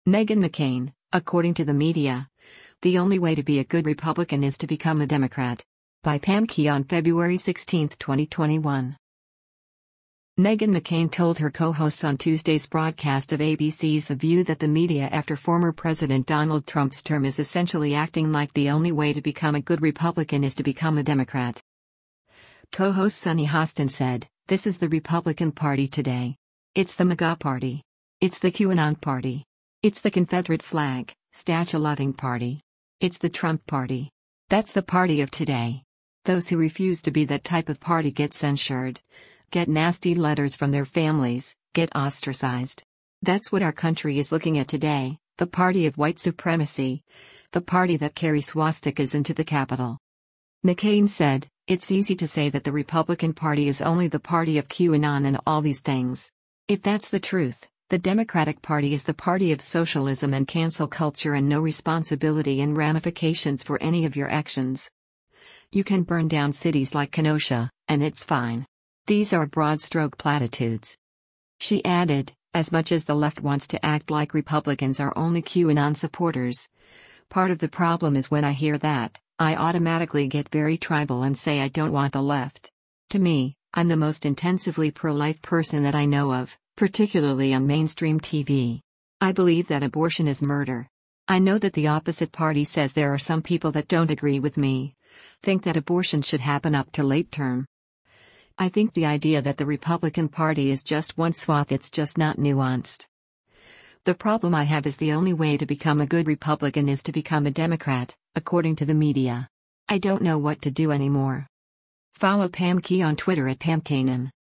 Meghan McCain told her co-hosts on Tuesday’s broadcast of ABC’s “The View” that the media after former President Donald Trump’s term is essentially acting like “the only way to become a good Republican is to become a Democrat.”